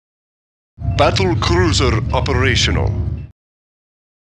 starcraft-battlecruiser-quotes-audiotrimmer_YasKSmW.mp3